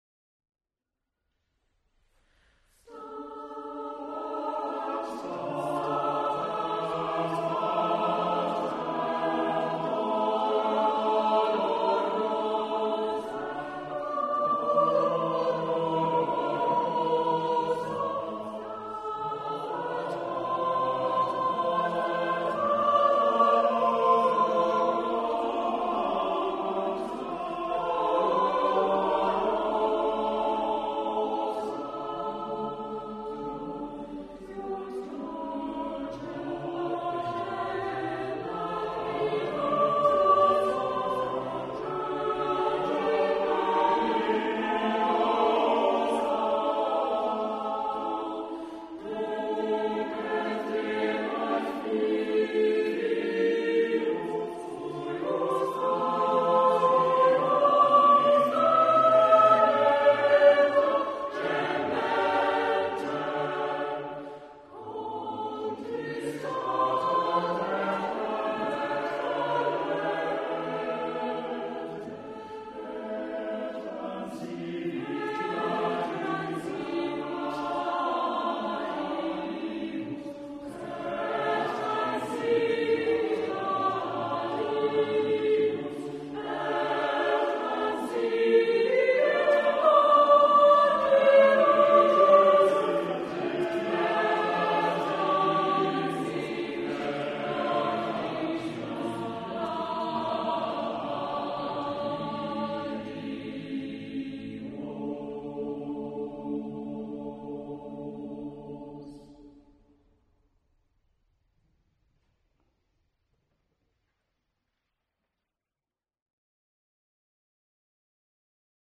Cherwell Singers Concert March 2007